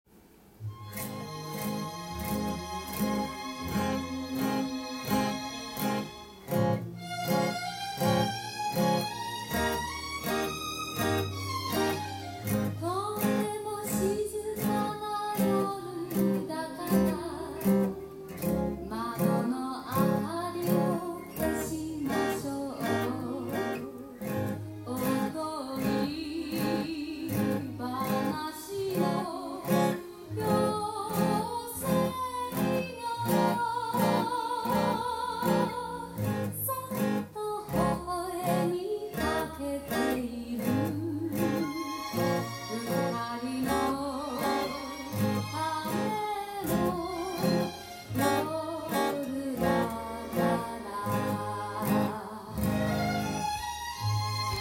音源に合わせて譜面通り弾いてみました
１カポで弾くとTAB譜通りのコードで演奏できます。